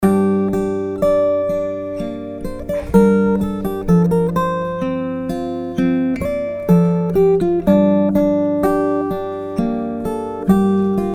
Конденсаторный микрофон тихо записывает.
Записал тестовые записи микрофона AKG через карту Steinberg UR22C напрямую и через внешний предусилитель M-Audio Buddy .
Микрофон был расположен на расстоянии от деки примерно 15-20см Вложения тест без преампа.mp3 тест без преампа.mp3 588,9 KB · Просмотры: 1.608 тест с преампом.mp3 тест с преампом.mp3 436,8 KB · Просмотры: 1.634